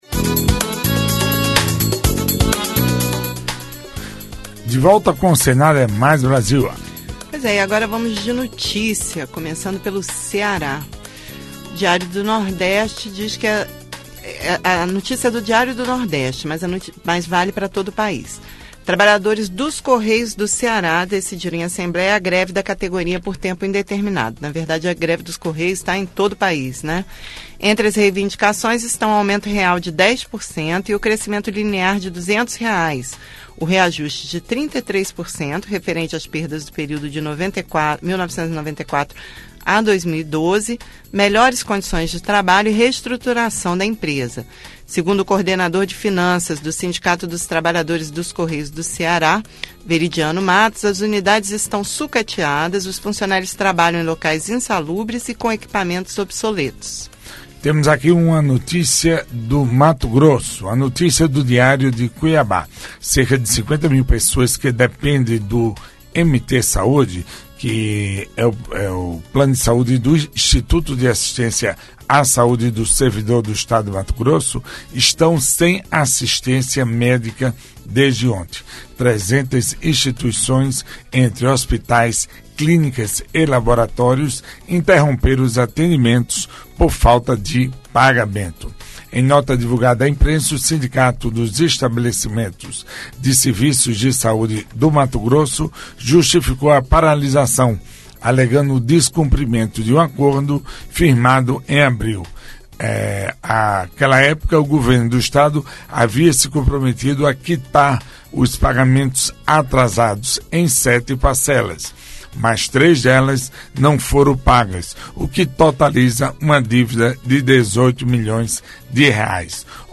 Notícias: giro pelos estados Minuto Cultural: Natal (RN) Projeto do Dia: Reforma do Código Penal Minuto Cultural: Rio Branco (AC) Viver da Terra: Novo Código Florestal. Entrevista